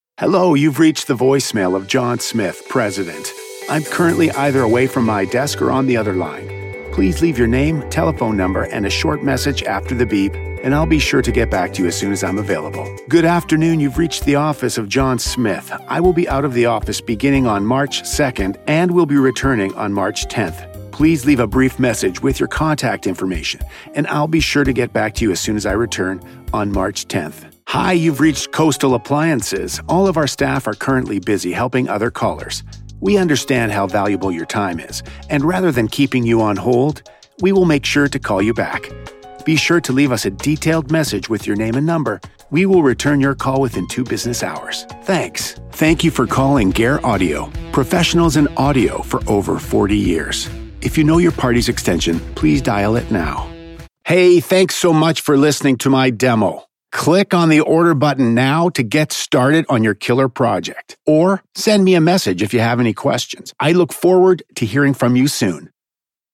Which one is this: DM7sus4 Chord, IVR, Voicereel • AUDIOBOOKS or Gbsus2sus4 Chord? IVR